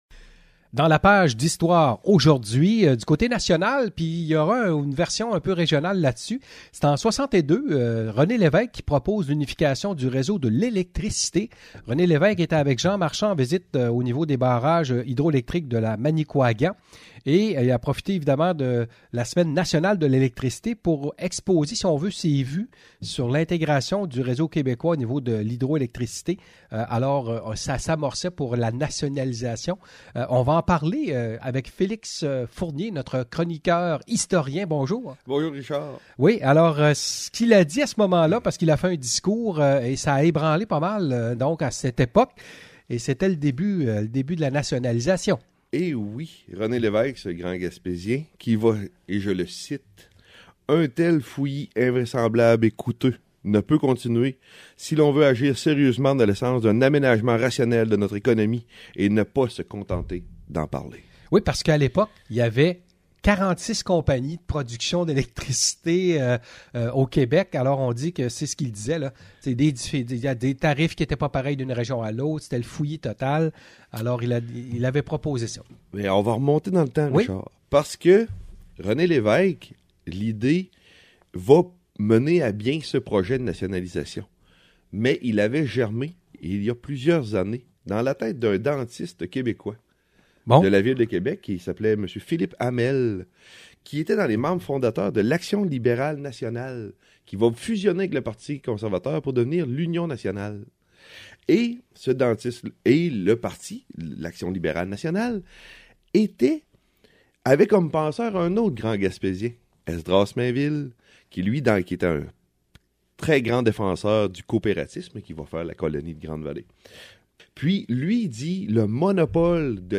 Le 13 février, c’était le début de la nationalisation de l’électricité avec le discours de René Levesque. Entrevue sur le sujet